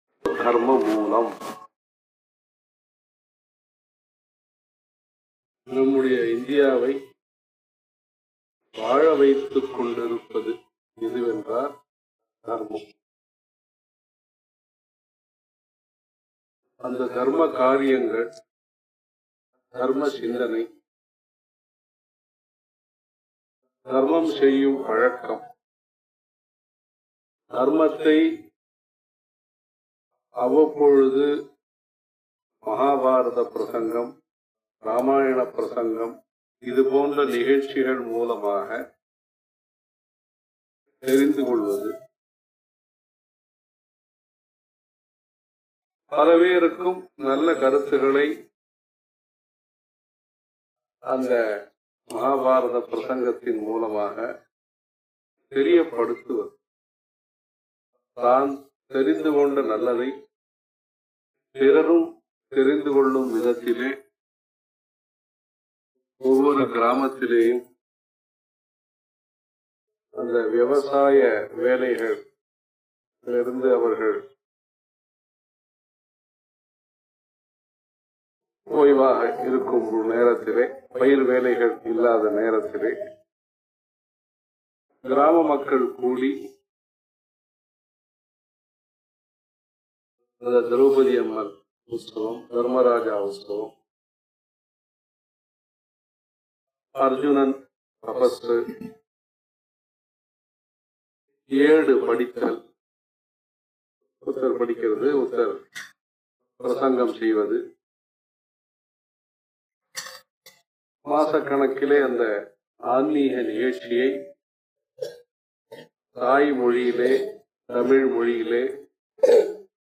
గుడిలో పూజాకార్యక్రమాలు జరిగాయి, జగద్గురువులు అనుగ్రహభాషణం చేశారు.
Melmaraikkattar ThiruKovil Anugraha Bhashanam.mp3